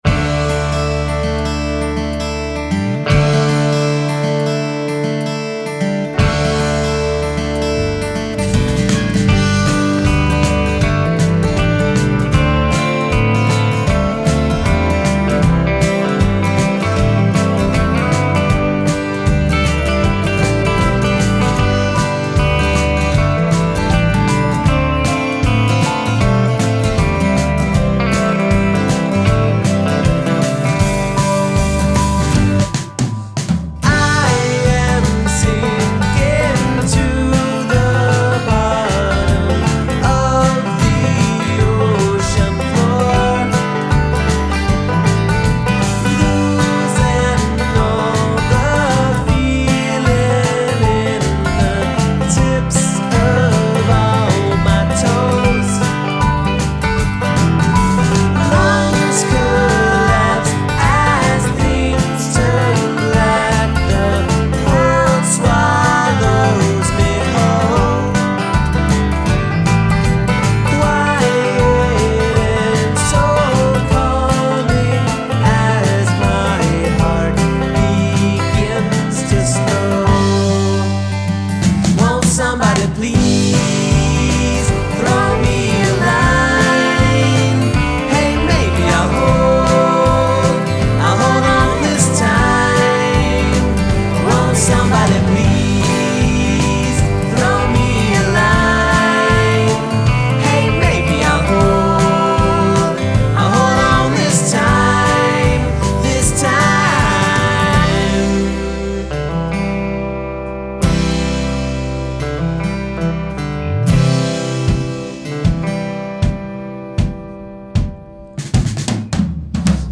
catchy folk rock band with a unique indie vibe
Beautiful harmonies, melodies, and sing-along choruses
catchy folk rock album with a unique indie vibe